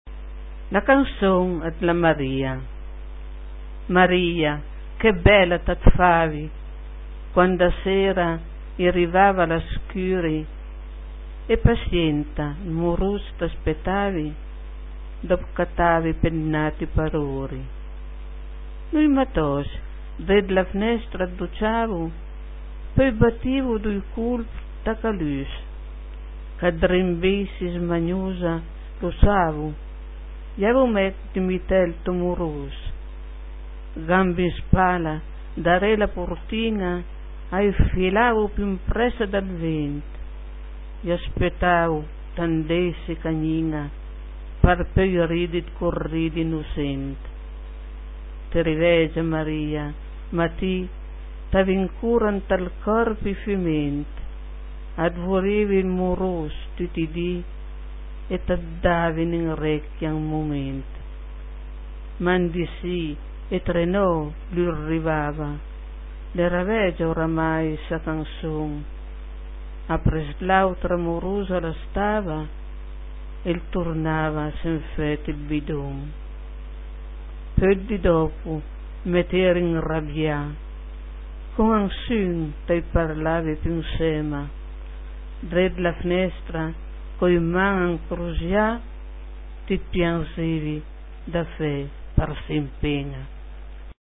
Cliché chi sùta par sénti la puizìa recità da l'autùr ...